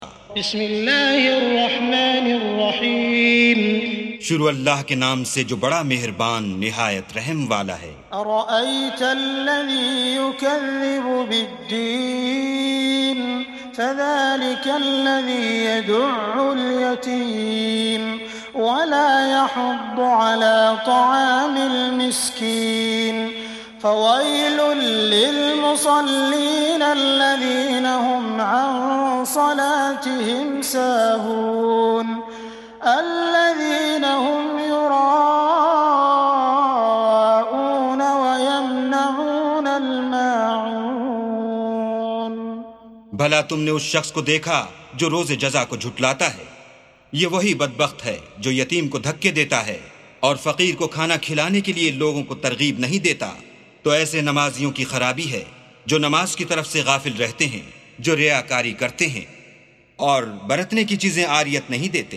سُورَةُ المَاعُونِ بصوت الشيخ السديس والشريم مترجم إلى الاردو